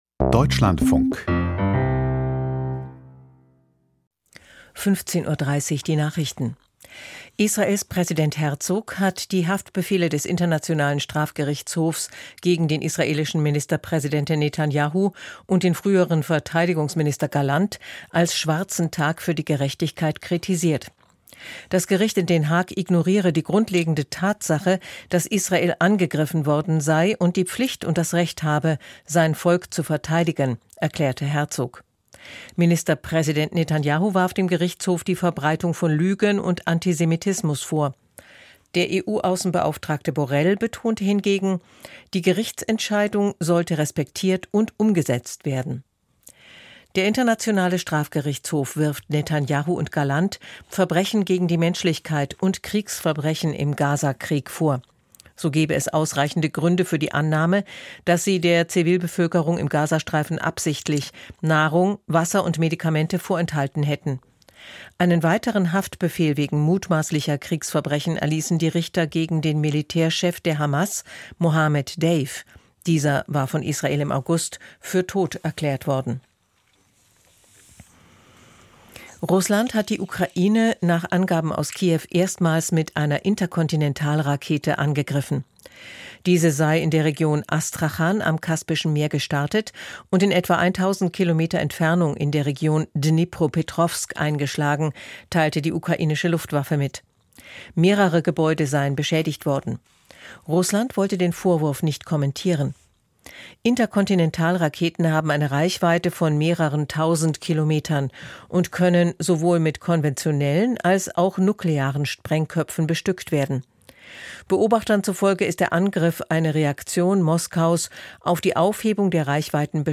Die Nachrichten 102 subscribers updated 27m ago Theo dõi Đăng ký theo dõi Nghe Đang phát Chia sẻ Đánh dấu tất cả (chưa) nghe ...